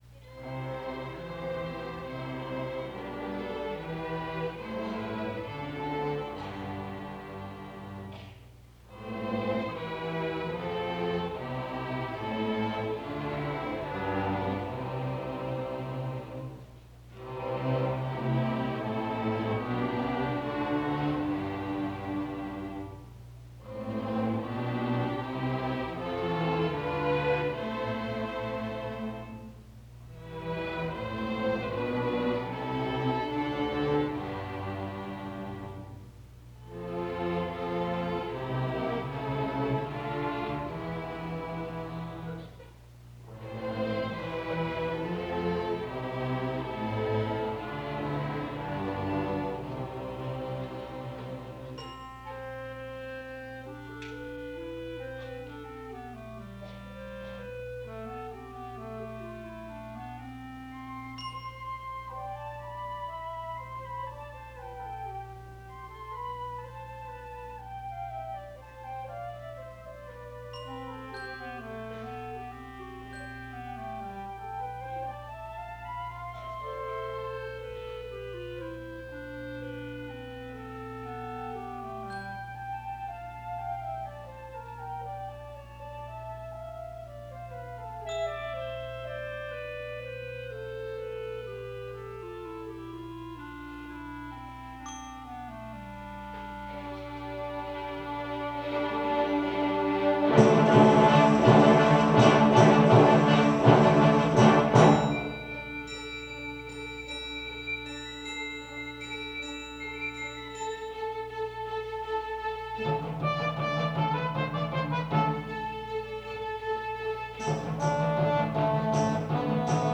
1972 Christmas Music Concert
The annual BHS Christmas concert, recorded live in the school auditorium by the music department staff on December 20. 1972.
Digitally mastered from an original stereo reel-to-reel source tape
03. BHS Orchestra - A Mighty Fortress....Vaclav Nelhybel.mp3